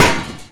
metal_barrel_hard1.wav